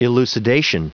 Prononciation du mot elucidation en anglais (fichier audio)
Prononciation du mot : elucidation